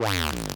JJ_PlungeFX.wav